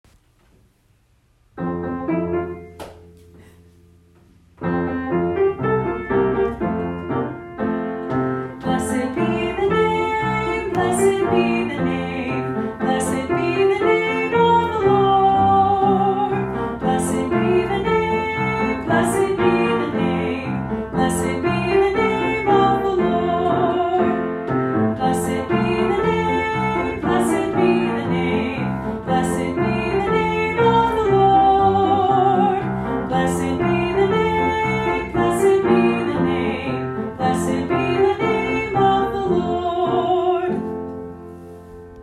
Scripture Songs